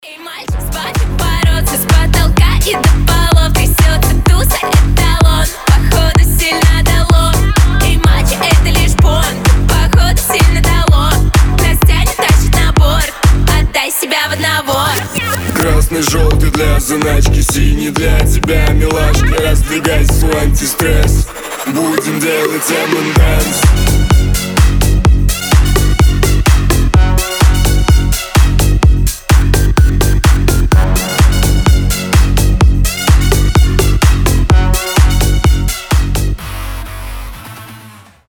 • Качество: 320, Stereo
веселые
дуэт
энергичные
быстрые
electro house
G-House